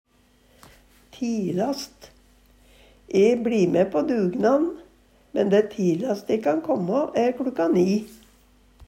tilast - Numedalsmål (en-US)